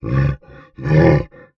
MONSTER_Effort_08_mono.wav